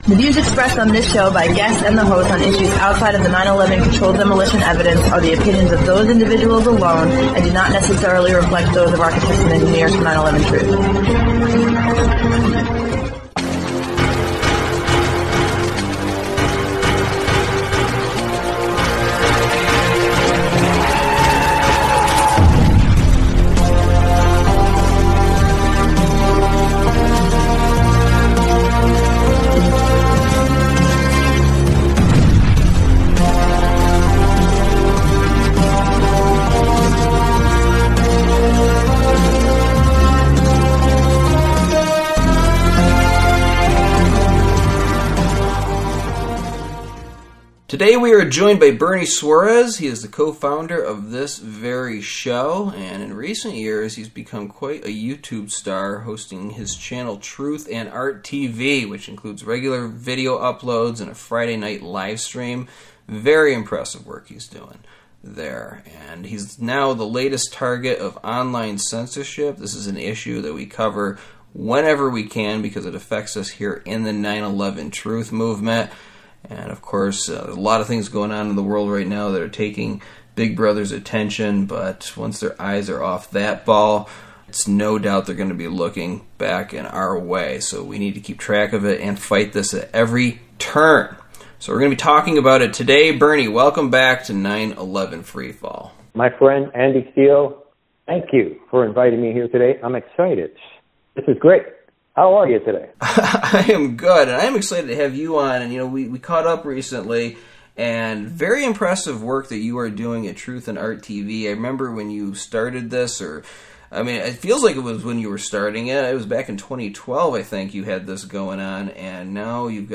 Talk Show Episode, Audio Podcast, 911 Free Fall and Latest Round of YouTube Censorship of Alt Media on , show guests , about YouTube Censorship of Alt Media, categorized as History,News,Politics & Government,Science,Society and Culture,Technology,Theory & Conspiracy